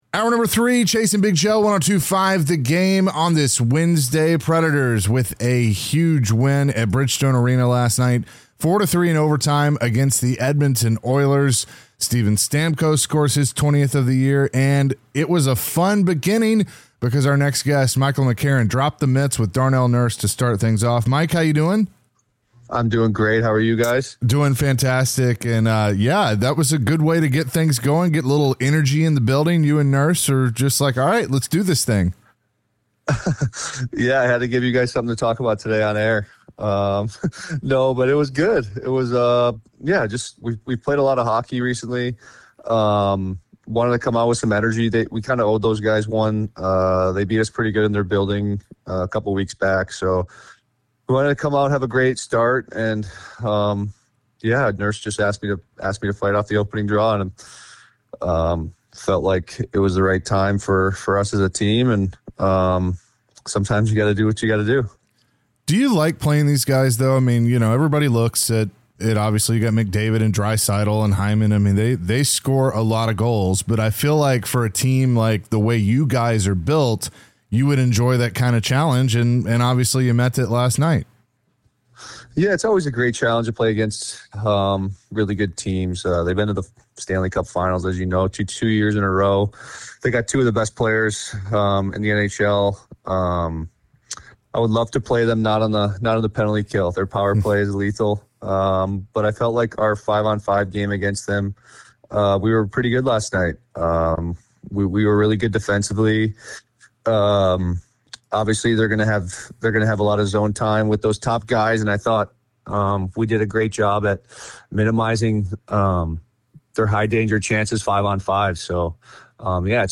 The guys chatted with Nashville Predators forward Michael McCarron. he shared his thoughts on the Preds big win and the camaraderie of the team.